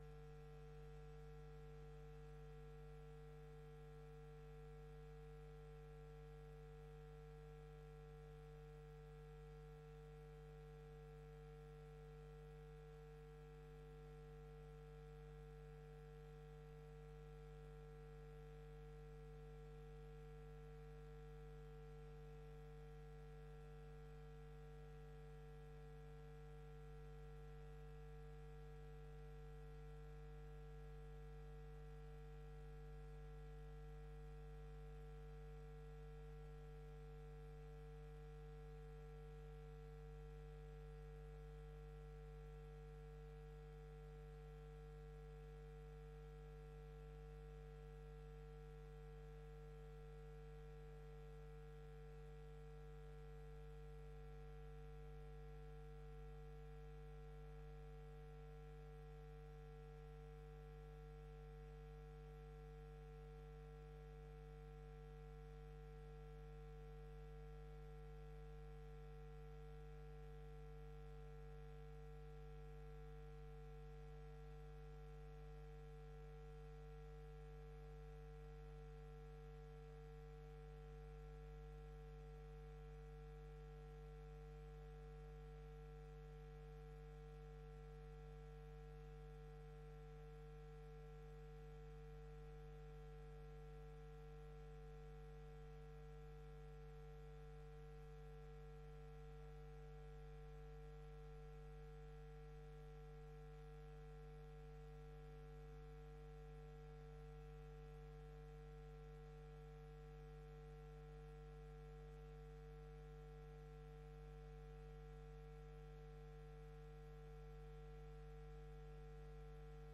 Gemeenteraad 11 februari 2025 19:30:00, Gemeente Hof van Twente